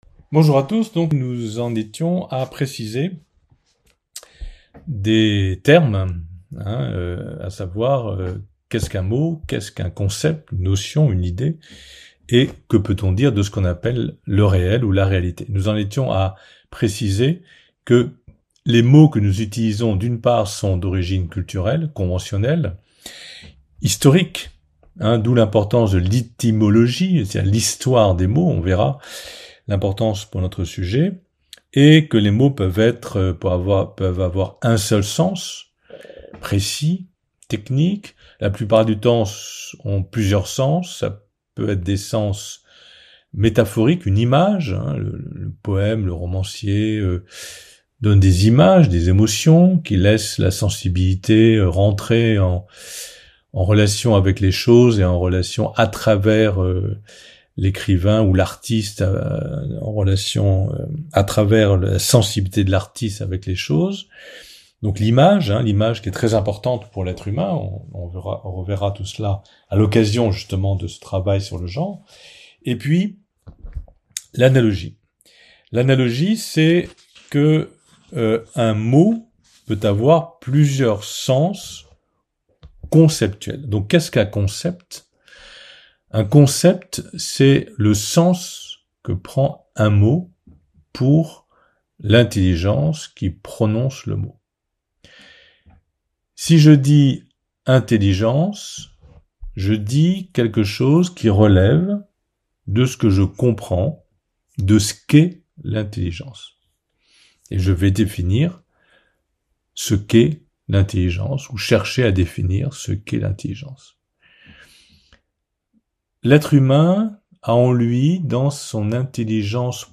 Conférence de la semaine